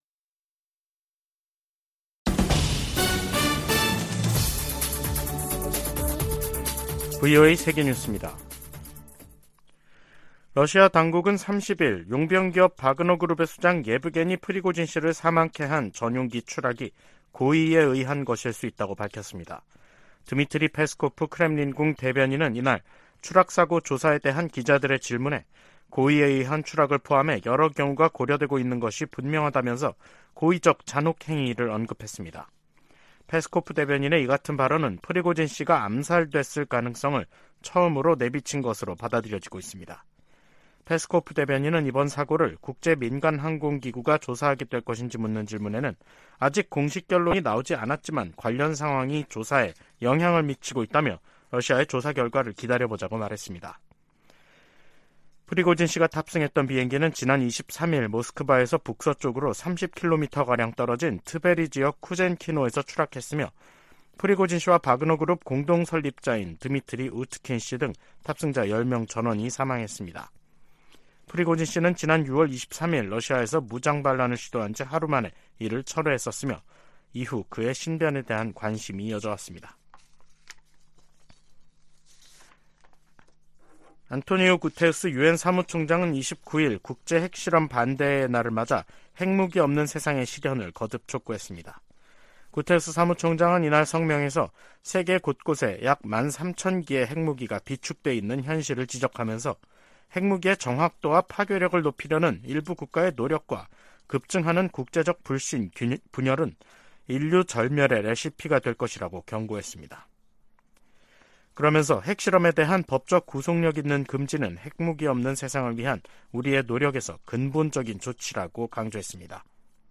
VOA 한국어 간판 뉴스 프로그램 '뉴스 투데이', 2023년 8월 30일 3부 방송입니다. 미국과 한국, 일본은 한반도 사안을 넘어 국제적으로 안보협력을 확대하고 있다고 백악관 조정관이 말했습니다. 미 국방부는 위성 발사 같은 북한의 모든 미사일 활동에 대한 경계를 늦추지 않을 것이라고 강조했습니다. 국제 핵실험 반대의 날을 맞아 여러 국제 기구들이 북한의 핵과 미사일 개발을 규탄했습니다.